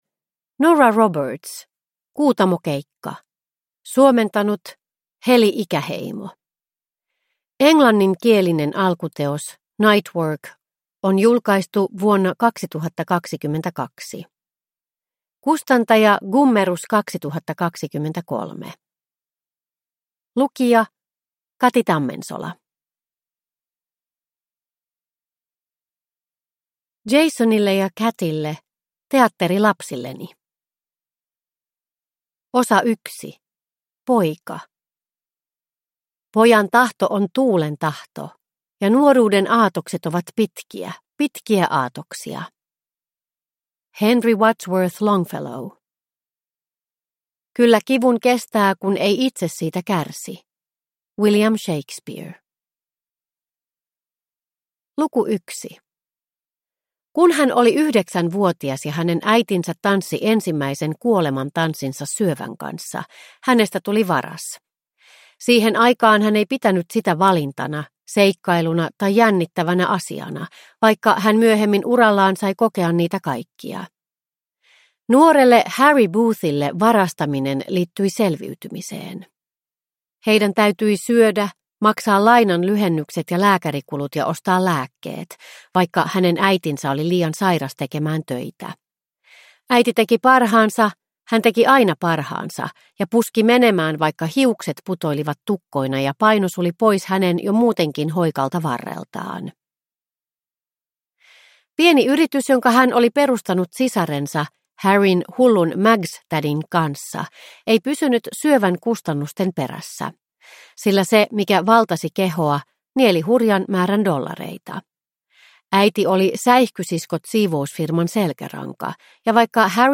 Kuutamokeikka – Ljudbok – Laddas ner